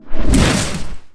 WAV · 50 KB · 單聲道 (1ch)